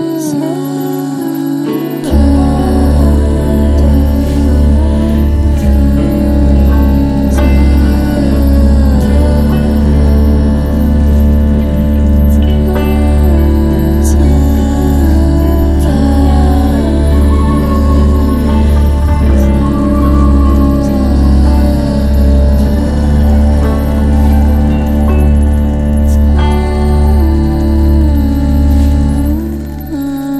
De retour en formation trio